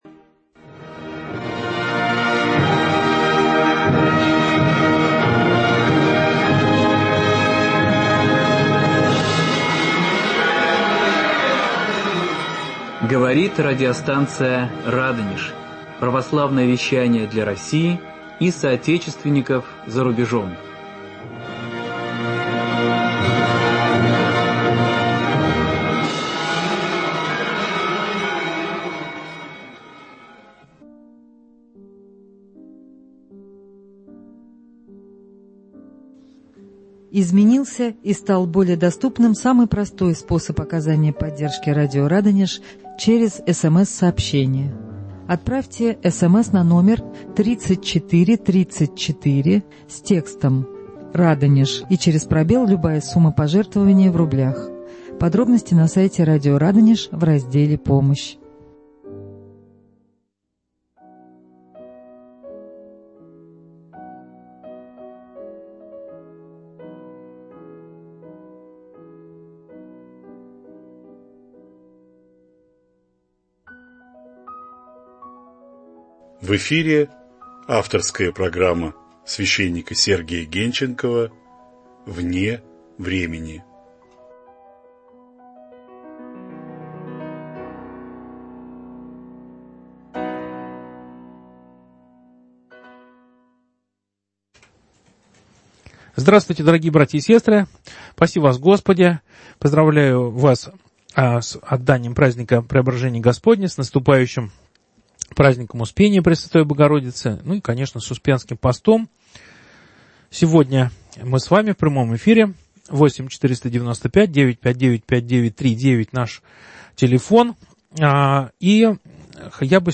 В прямом эфире радиостанции "Радонеж" новый выпуск авторской программы